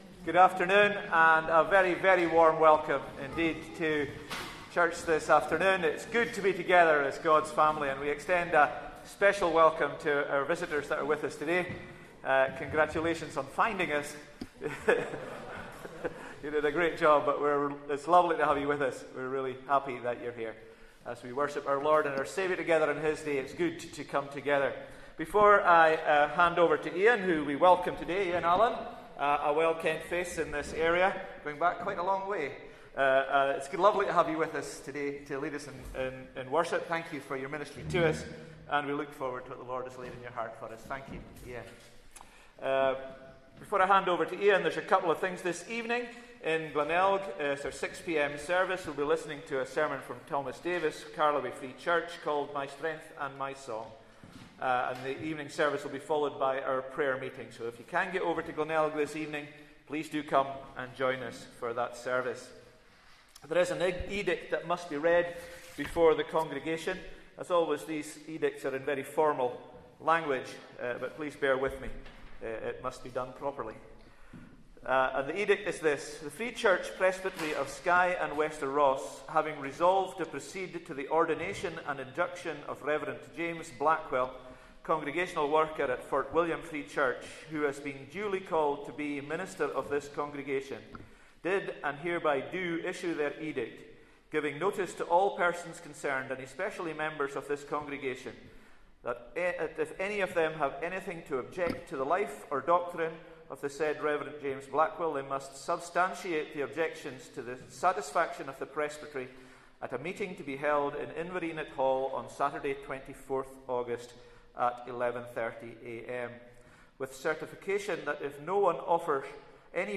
Sunday Service 11th August 2024